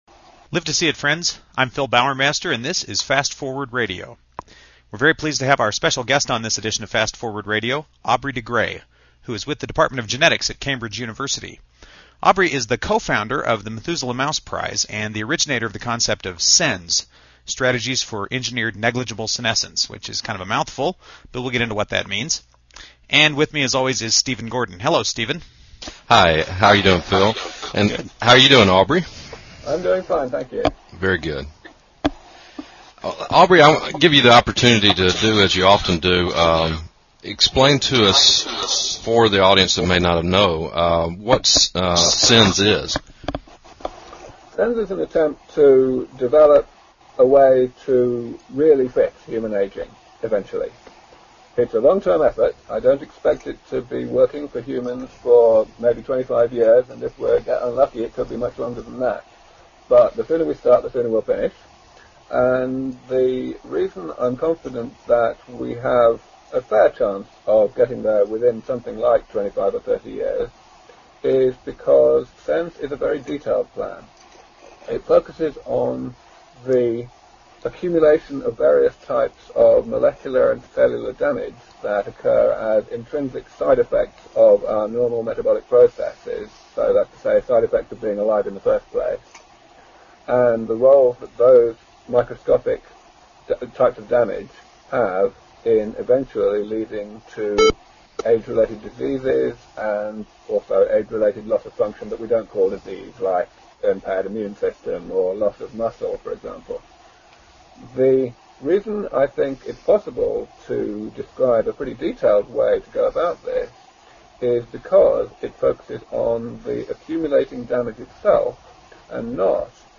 A Conversation With Aubrey de Grey
Fortunately Dr. de Grey did not echo.